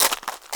Index of /90_sSampleCDs/AKAI S6000 CD-ROM - Volume 6/Human/FOOTSTEPS_1
GRAVEL 2.WAV